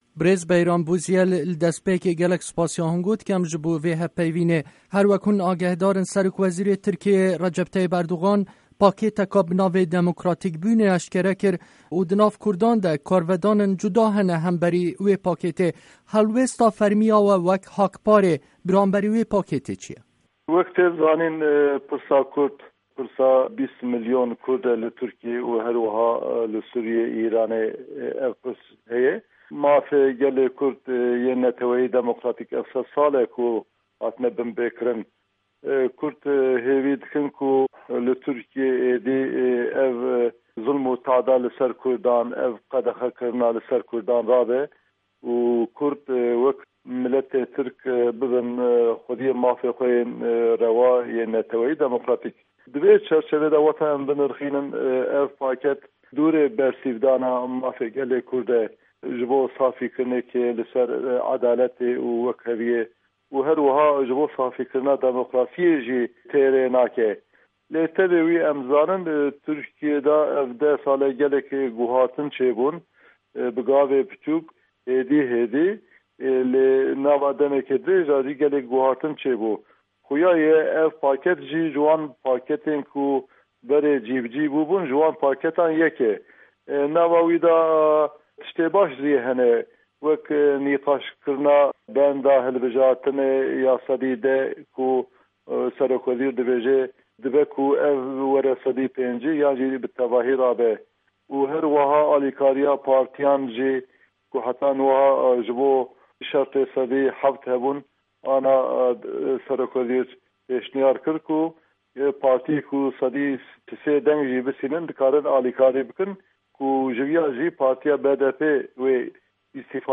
Hevpeyvîn bi birêz Bayram Bozyel re